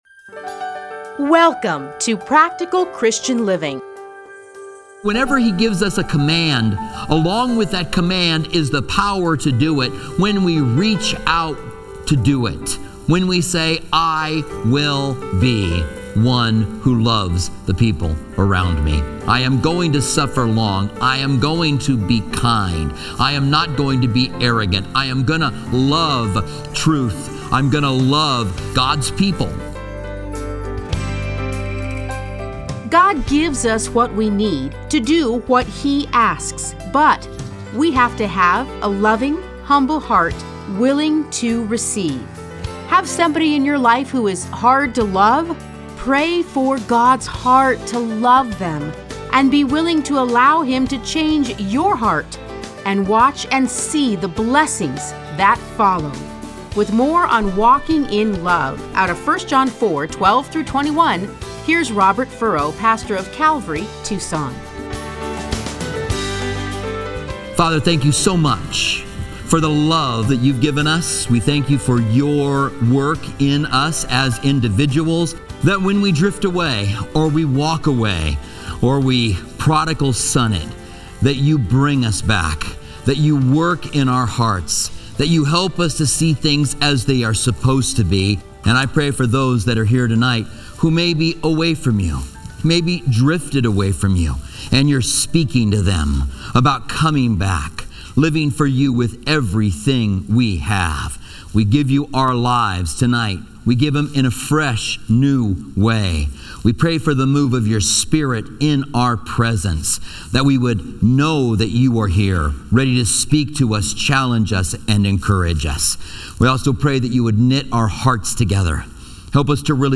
Listen to a teaching from 1 John 4:12-21.